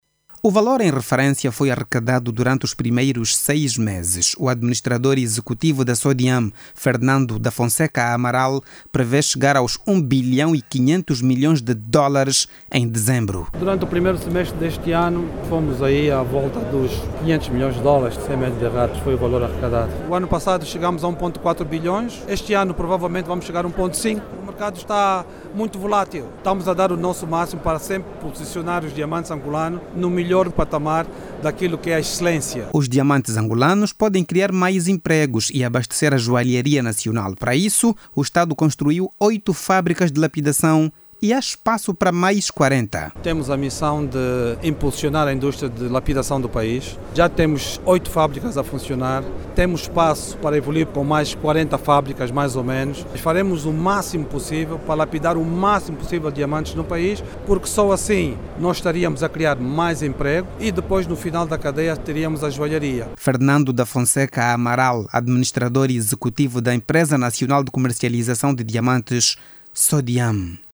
A expectativa é que até Dezembro, a empresa consiga arrecadar mais de um bilião de dólares. A reportagem